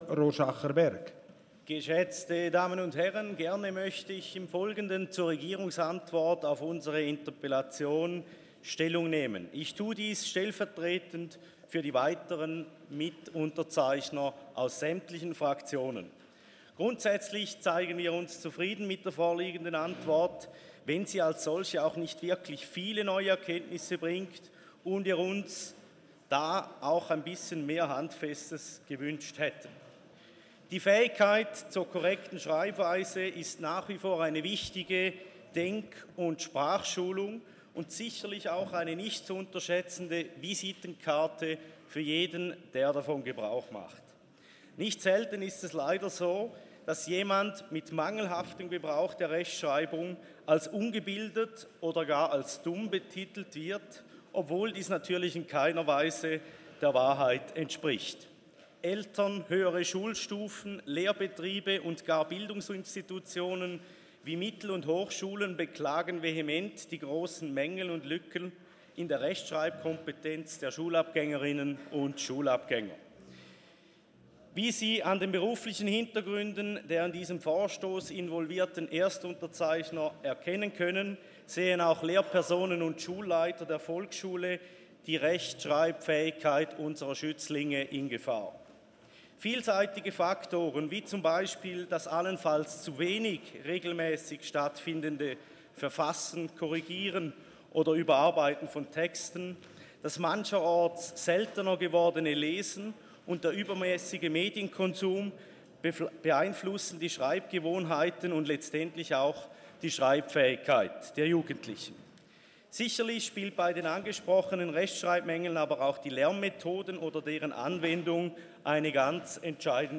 23.4.2019Wortmeldung
Session des Kantonsrates vom 23. und 24. April 2019